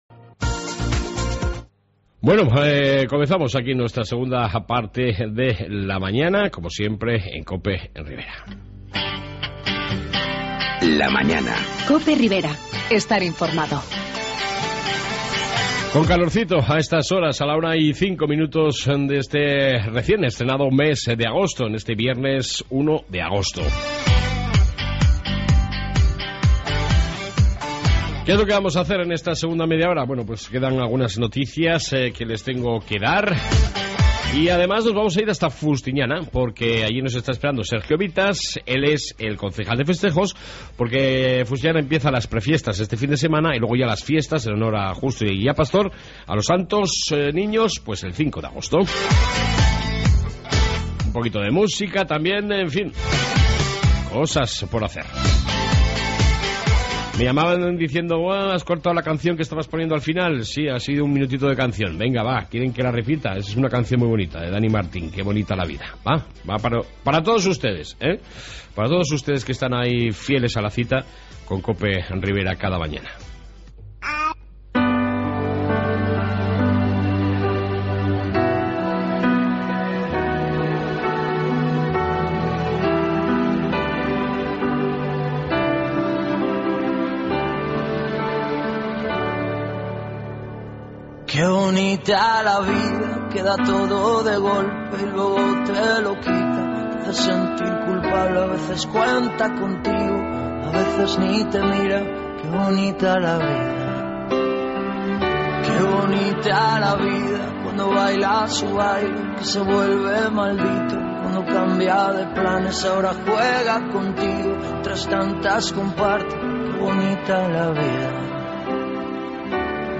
AUDIO: en esta 2 parte Información y entrevista sobre Fiestas de Fustiñana...